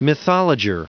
Prononciation du mot mythologer en anglais (fichier audio)
Prononciation du mot : mythologer